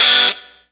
Track 02 - Guitar Stab OS 06.wav